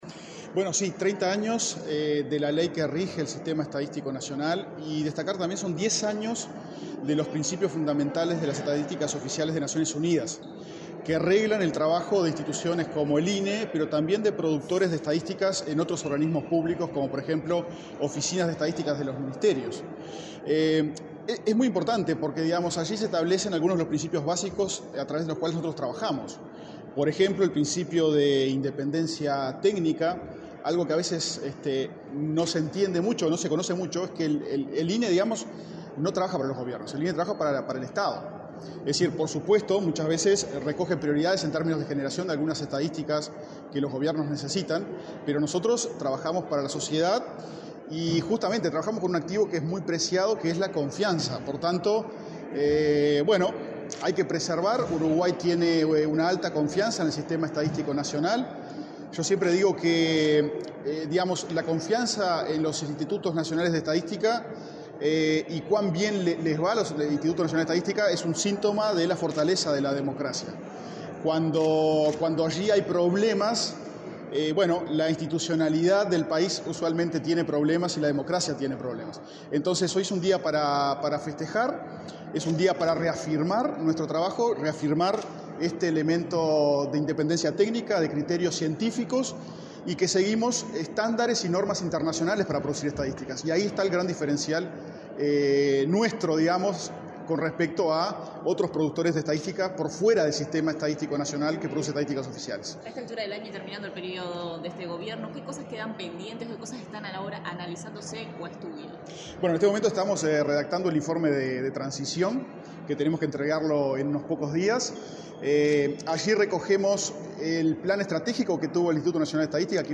Declaraciones del director del INE, Diego Aboal
Declaraciones del director del INE, Diego Aboal 27/11/2024 Compartir Facebook X Copiar enlace WhatsApp LinkedIn Este miércoles 27 en la Torre Ejecutiva, el director del Instituto Nacional de Estadística (INE), Diego Aboal, dialogó con la prensa, antes de participar en el acto conmemorativo de los 30 años del Sistema Estadístico Nacional y los 10 años de los Principios Fundamentales de las Estadísticas Oficiales de la Organización de las Naciones Unidas.